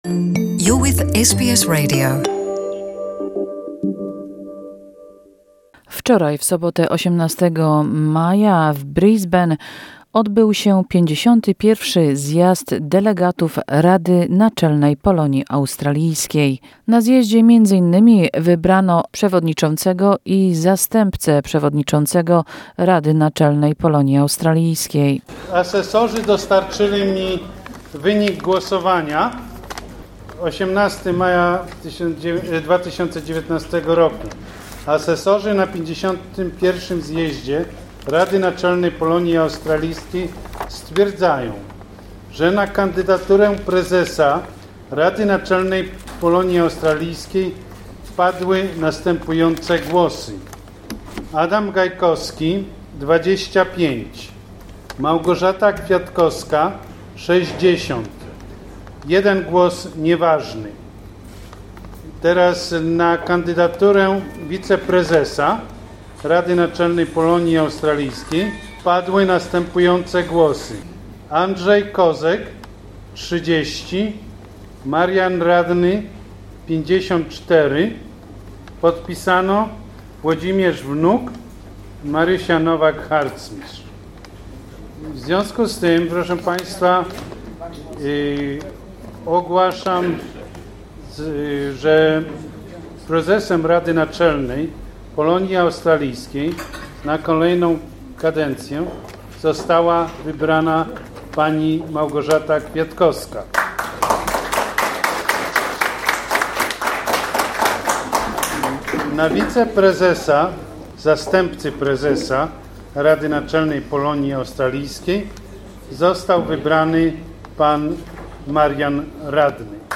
The 51th annual meeting of the council was held in Brisbane.